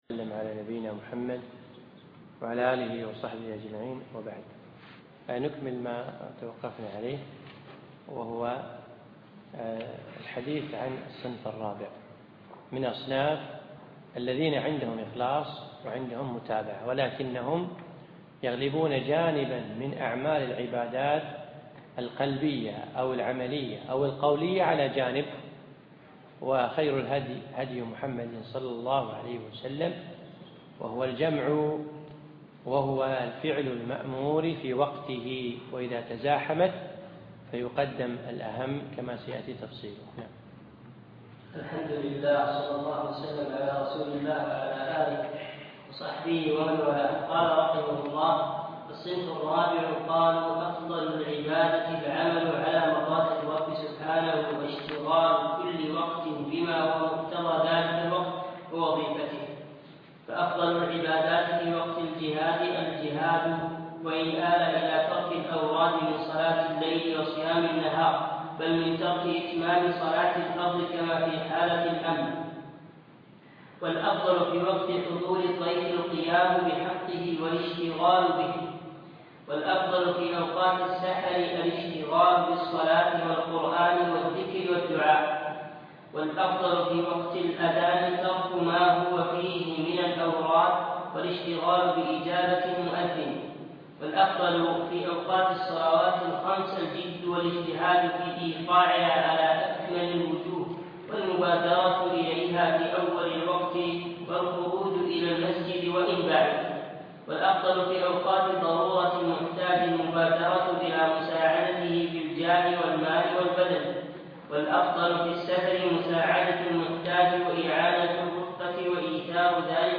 الدرس السادس والأخير